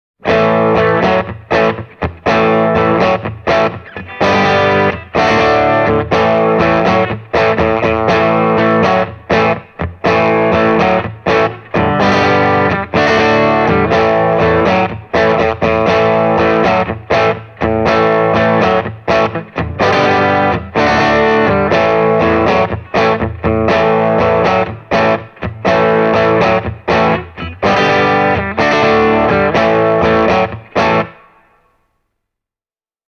Channel 1 will take you from clean all the way to Rockbilly-style breakup and traditional Blues overdrive, while Channel 2 offers more than enough dirt for chunky Rock tones.
Here’s Channel 1 at full gain (Casino and Melody Maker SG):